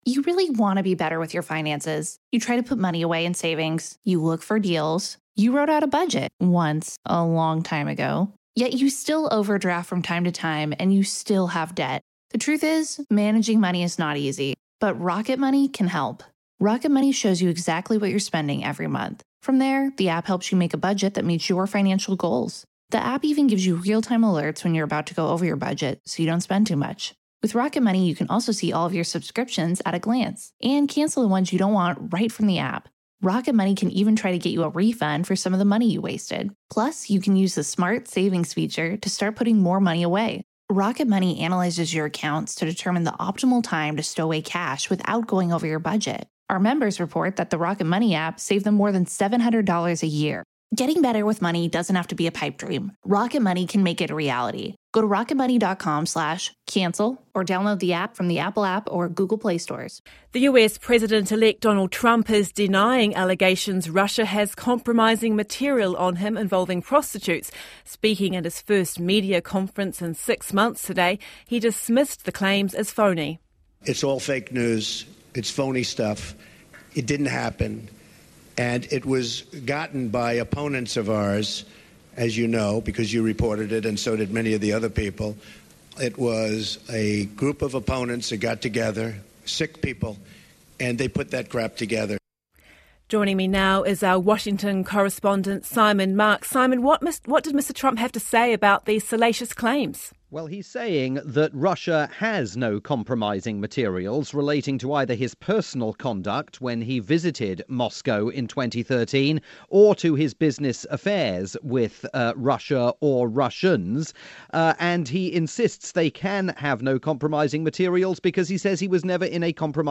digest of the Trump press conference aired on Radio New Zealand's "Summer Report"